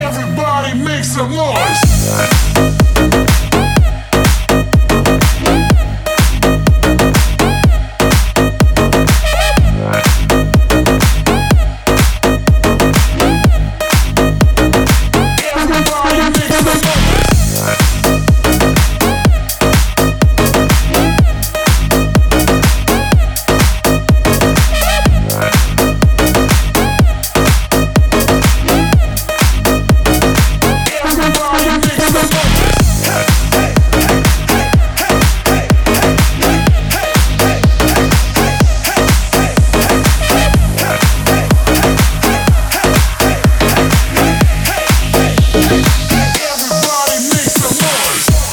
• Качество: 320, Stereo
громкие
dancehall
Club House
Big Room
electro house